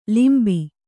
♪ limbi